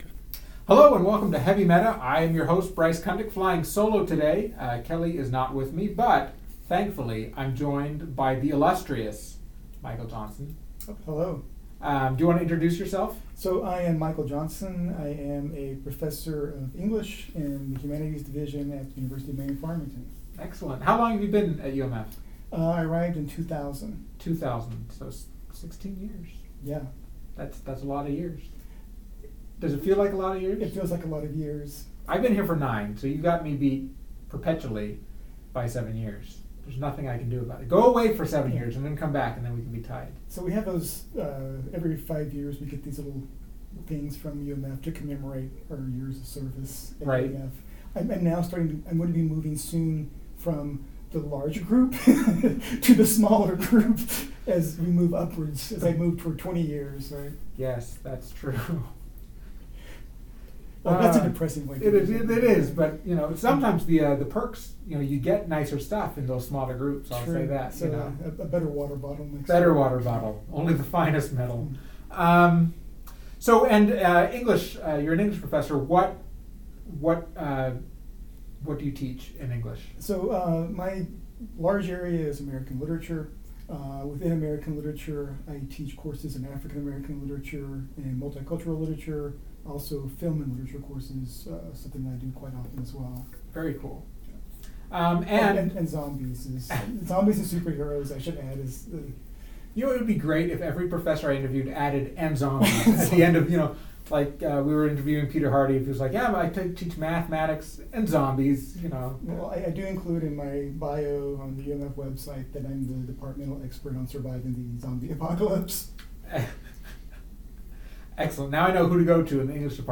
Another interview this week!